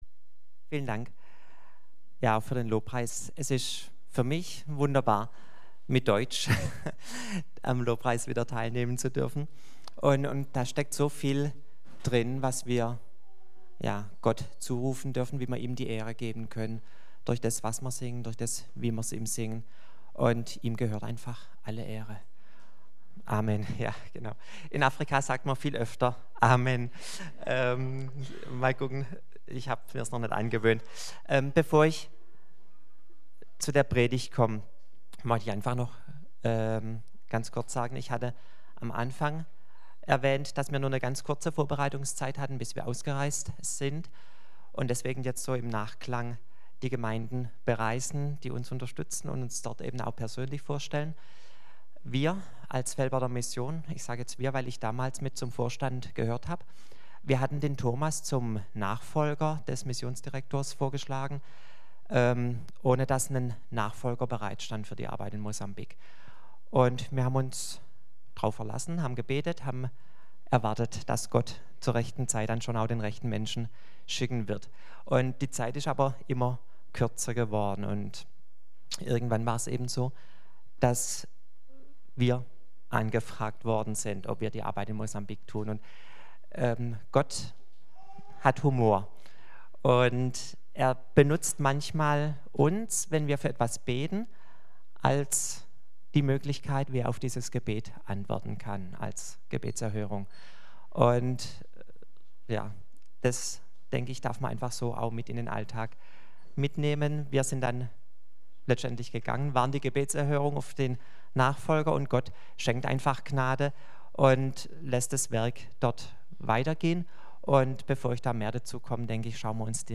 Kirche am Ostbahnhof, Am Ostbahnhof 1, 38678 Clausthal-Zellerfeld, Mitglied im Bund Freikirchlicher Pfingstgemeinden KdöR
Missionsbericht Mosambik